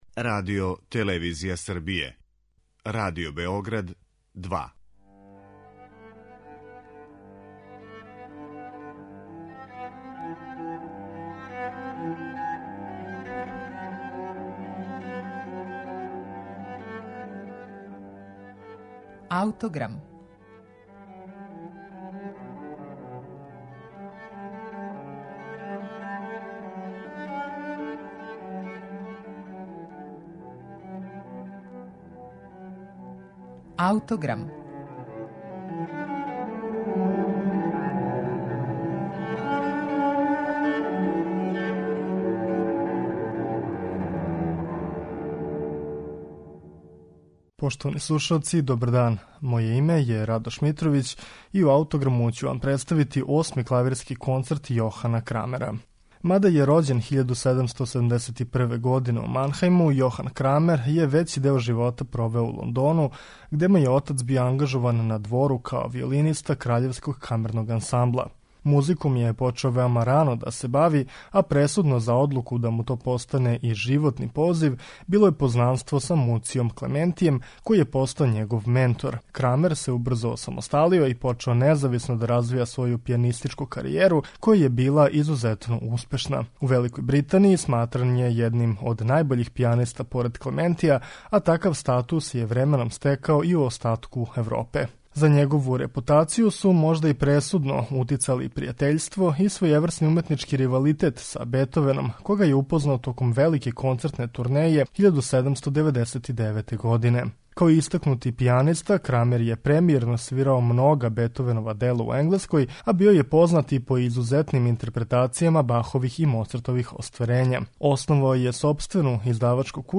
Осми клавирски концерт